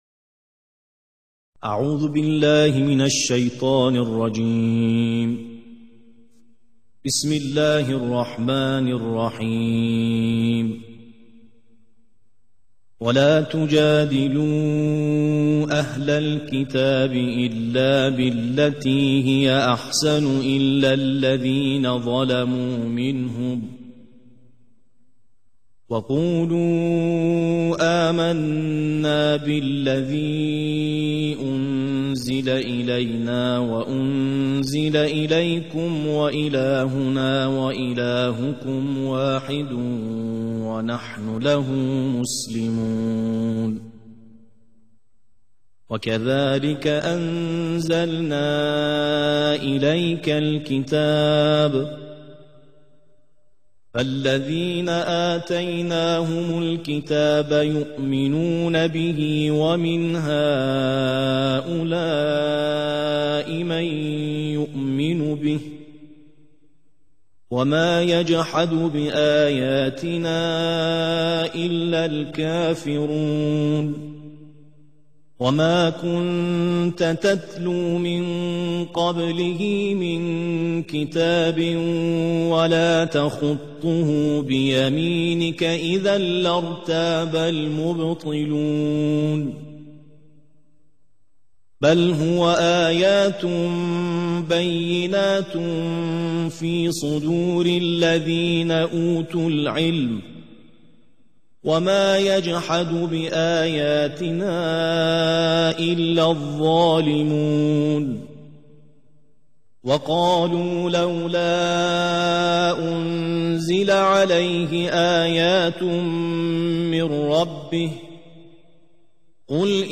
ترتیل جزء بیست و یکم